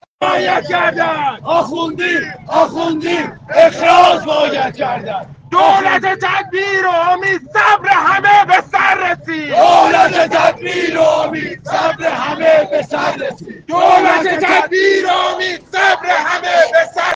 شعارهای مردم مقابل مجلس در حمایت از استیضاح آخوندی صوت - تسنیم
تعدادی از متقاضیان مسکن مهر که مقابل ساختمان مجلس تجمع کردند با سر دادن فریاد الله اکبر خواستار رای مثبت نمایندگان خود به استیضاح وزیر راه شدند.
متقاضیان مسکن مهر همچنین با فریادهای الله اکبر به دولت یادآوری می‌کنند که صبر همه به صبر آمده است.